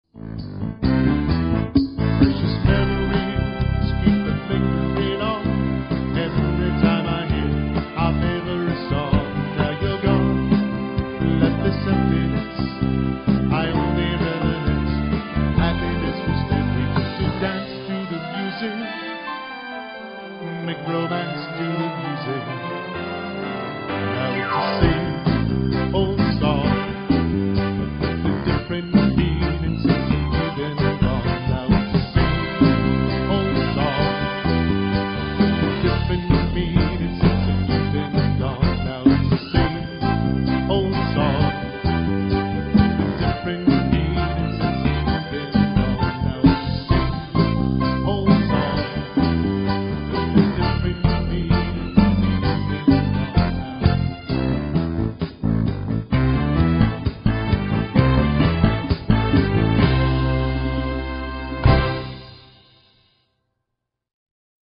Variety Group w/Vocals:
Motown